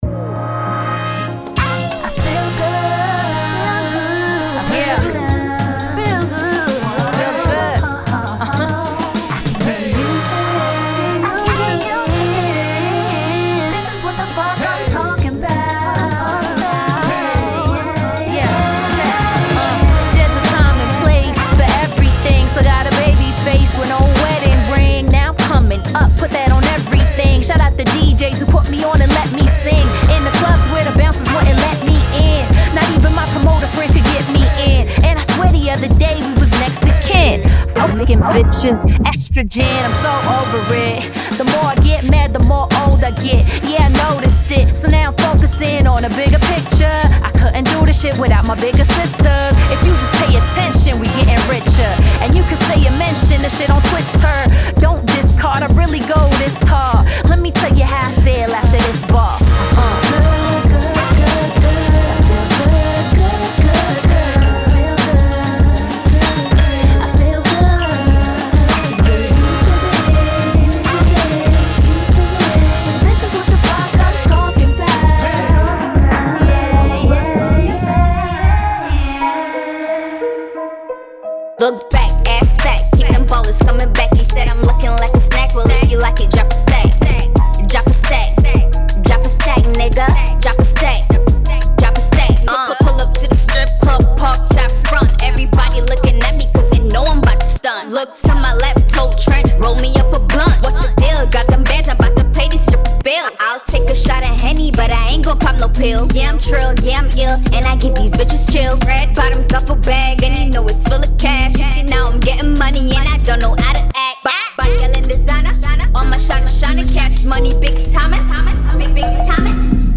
Genre:Hip Hop
リアルなラップ。
リアルな女性。
アメリカを拠点とする11人の女性MCが、それぞれ独自のスタイルでヴァースを披露しています。
本物のラッパーたちが、力強いリリック、甘いフック、多彩なフローをあらゆる角度から届けています。
安定したライム、歌唱フック、泥臭いデリバリー、そしてクリーンな自信を持っています。
212 Vocal Loops